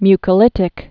(mykə-lĭtĭk)